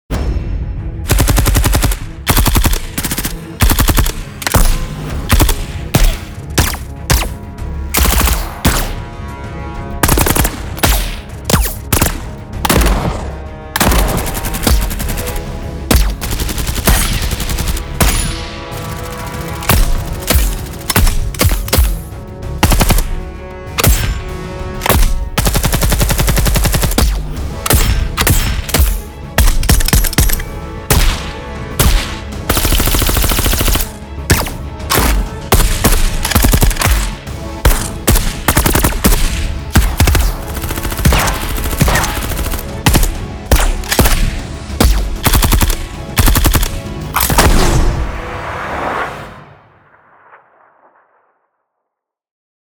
Более 10 ГБ звуковых эффектов оружия с глушителем
Механика: Фокус звука лежит на механике оружия - холодного, бескомпромиссного и очень близкого звука к реалиям.
Самый реалистичный и хорошо сбалансированный звук приглушенного оружия.
Вы услышите типичный высокочастотный шип в приглушенном выстреле оружия.
Проектная версия также содержит записи автоматической съемки и впечатляющих очередей выстрелов.
boom-silencers-sound-effects.mp3